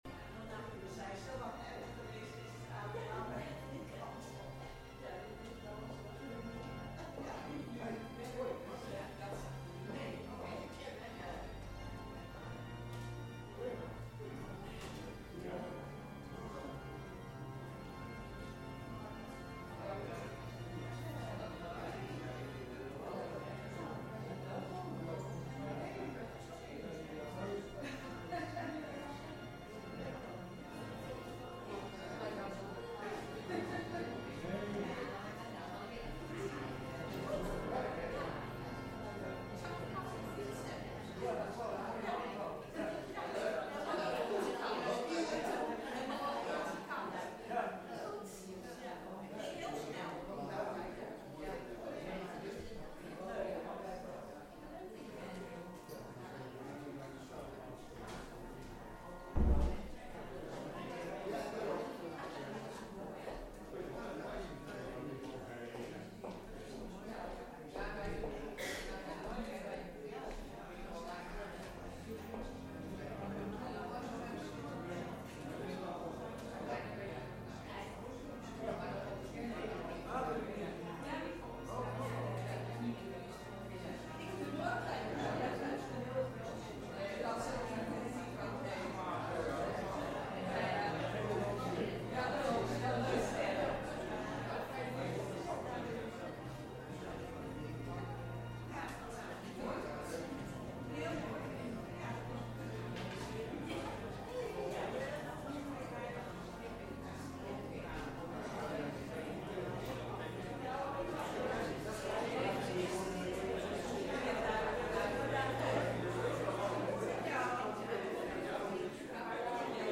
Diensten beluisteren
Elke zondag om 10.00 uur komt de gemeente samen in een kerkdienst.
Tijdens de samenkomsten is er veel aandacht voor muziek, maar ook voor het lezen van Gods woord en het overdenken hiervan. We zingen voornamelijk uit Opwekking en de Johannes de Heer bundel.